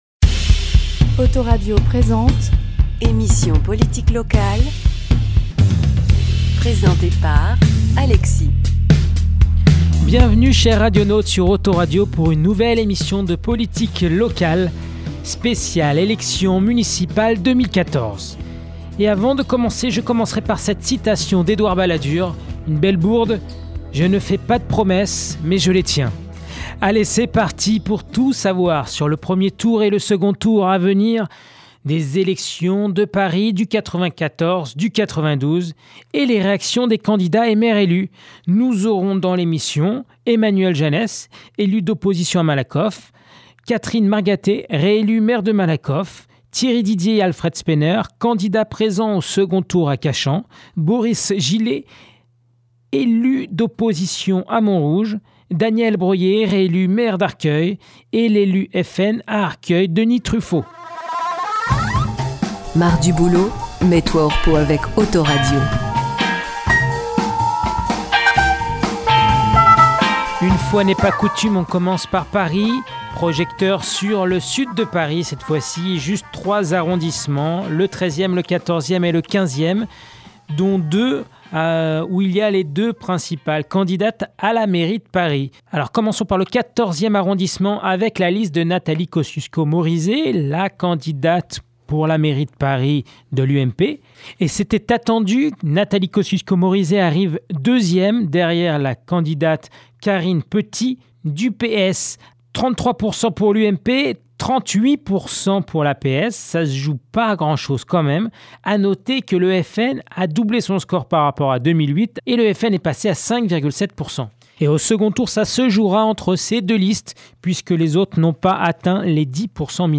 Emission politique locale